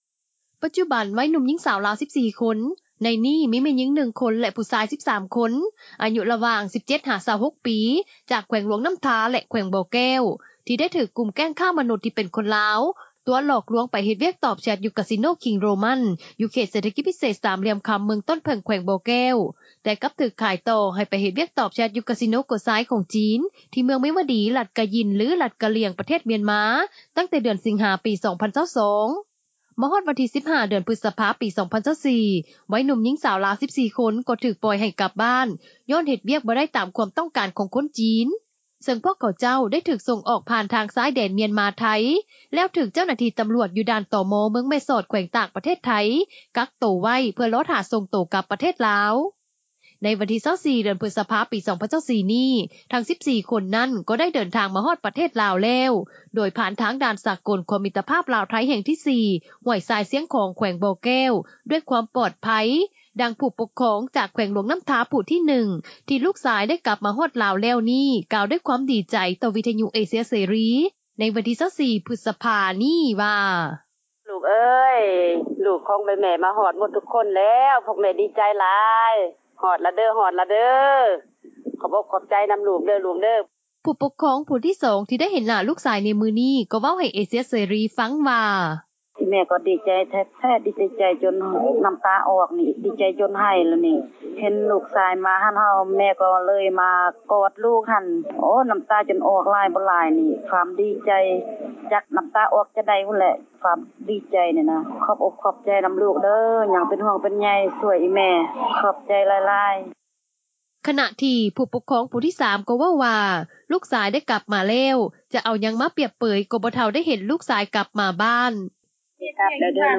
ໄວໜຸ່ມ-ຍິງສາວ ລາວ 14 ຄົນ ທີ່ຕິດຄ້າງຢູ່ປະເທດມຽນມາ ເດີນທາງມາເຖິງລາວແລ້ວ — ຂ່າວລາວ ວິທຍຸເອເຊັຽເສຣີ ພາສາລາວ